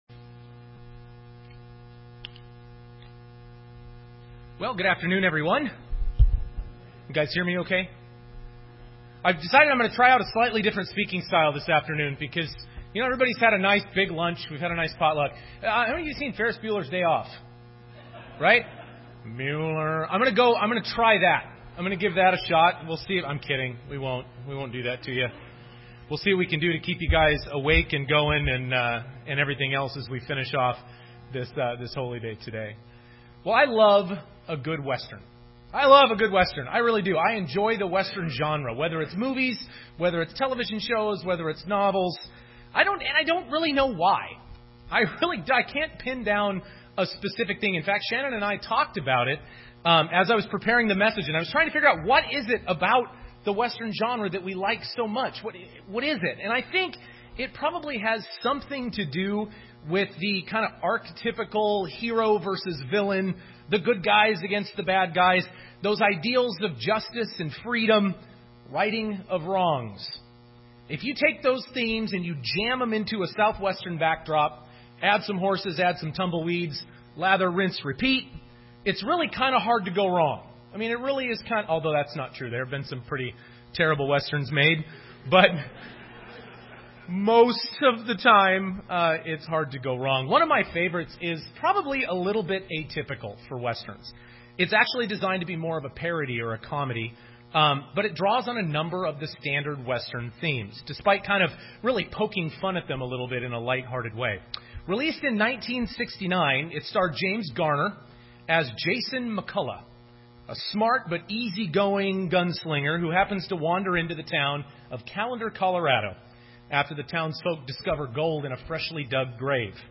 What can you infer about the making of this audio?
This sermon was given at the Bigfork, Montana 2016 Feast site.